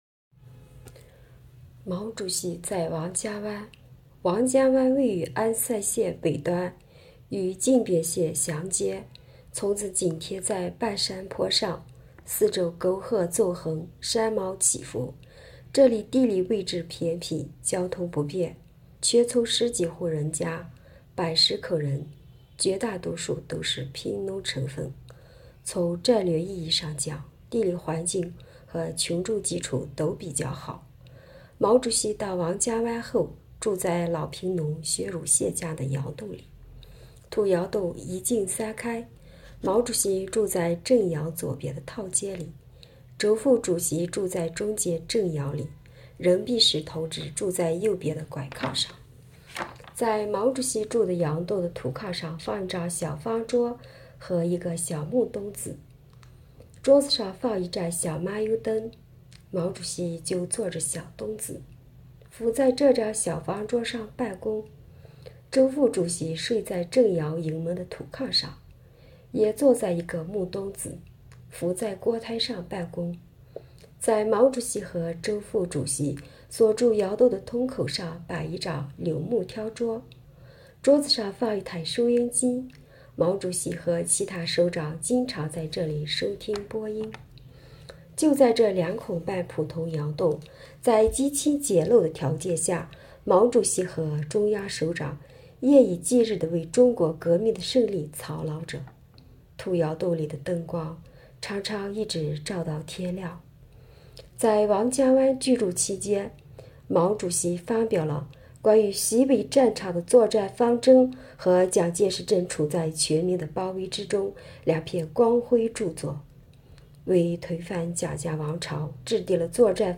【建党100年】红色档案诵读展播：毛主席在王家湾